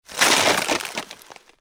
Crunch.wav